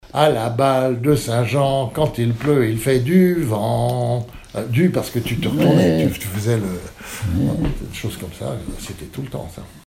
Enfantines - rondes et jeux
enfantine : jeu de balle
Pièce musicale inédite